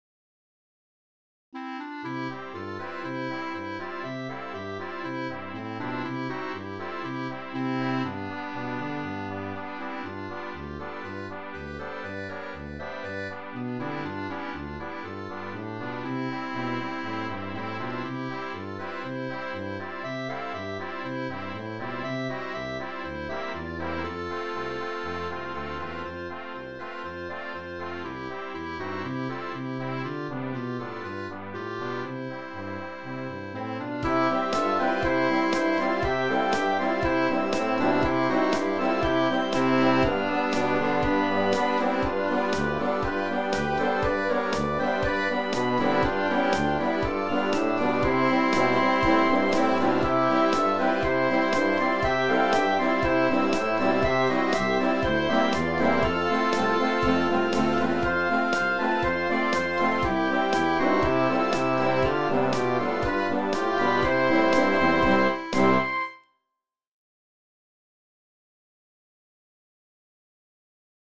Cpomposed with an M-Audio keyboard and the FINALE composition program
POLKA MUSIC